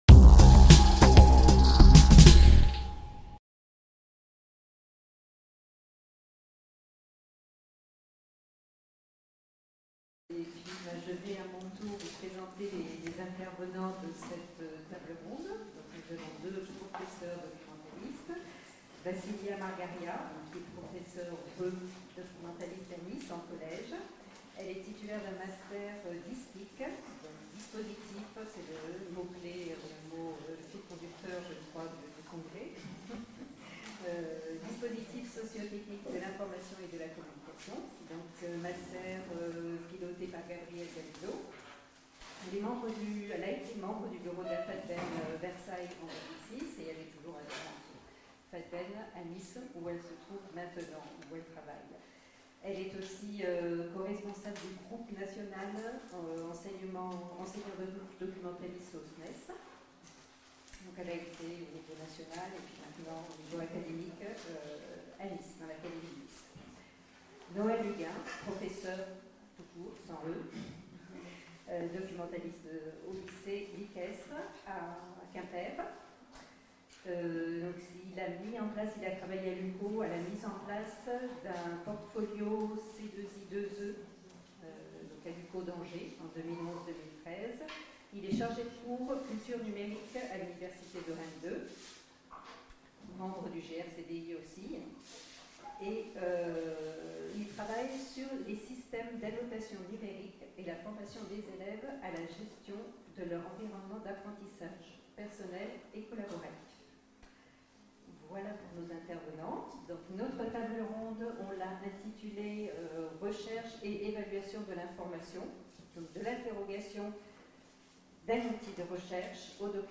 TABLE RONDE Animatrice